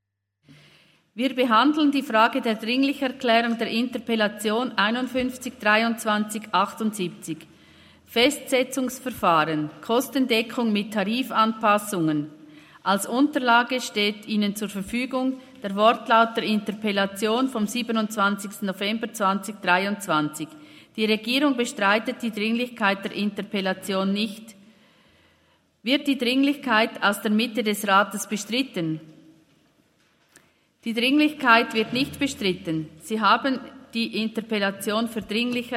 28.11.2023Wortmeldung
Session des Kantonsrates vom 27. bis 29. November 2023, Wintersession